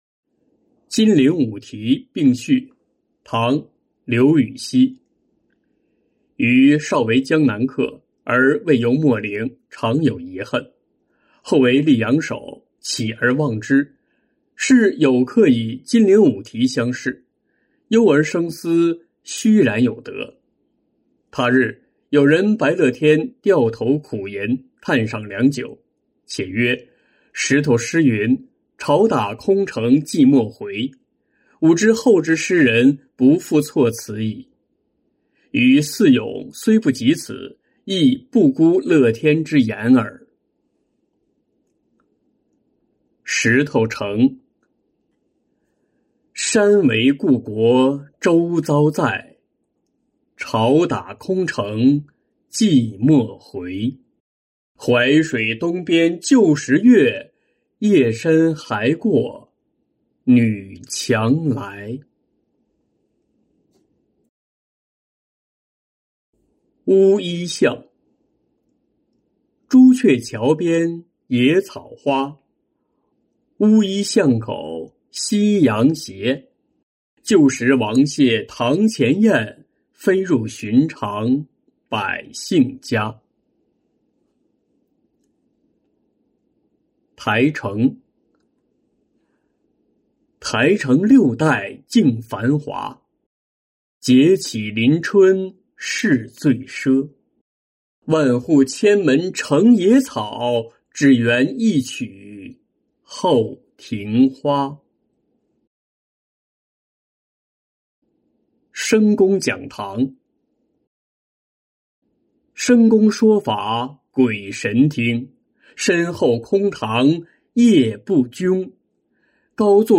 金陵五题·并序-音频朗读